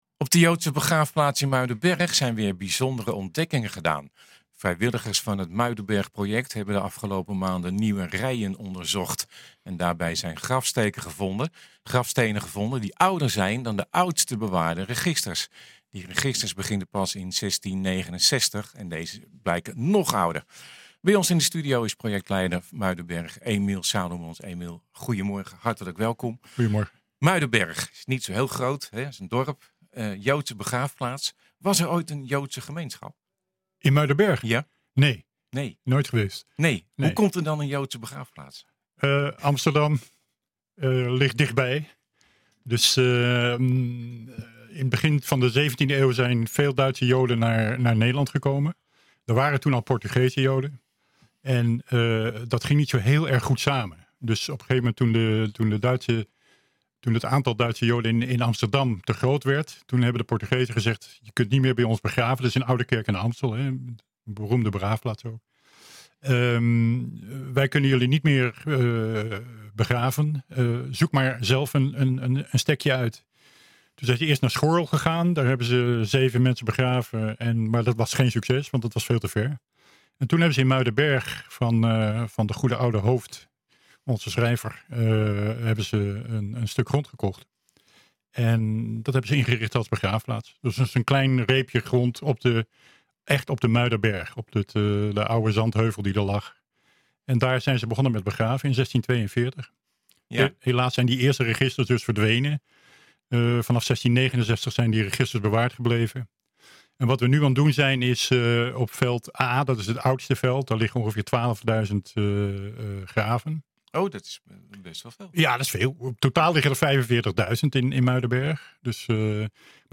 Bij ons in de studio